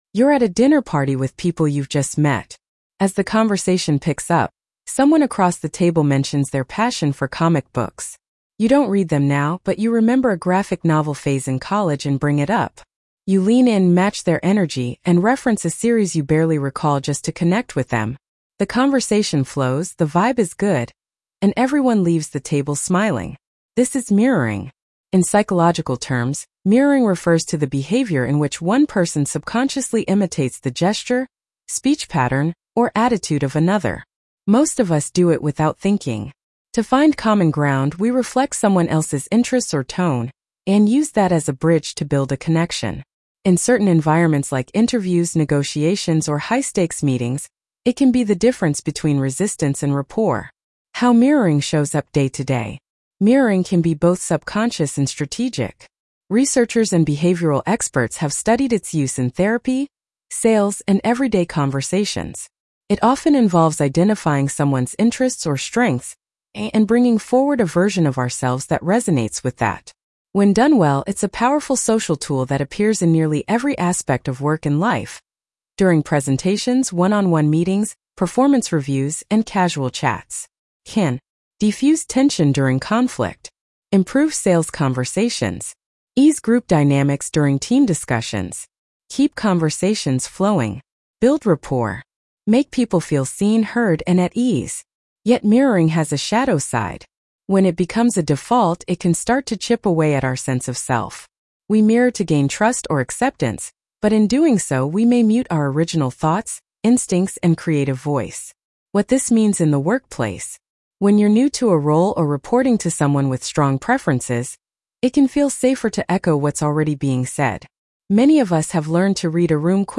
The Power and Peril of Mirroring How to Stay True to Yourself at Work Blog Narration.mp3